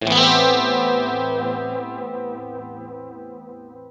09 Jazzy Five (open).wav